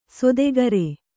♪ sodegare